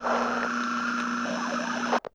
nerfs_psynoise2.ogg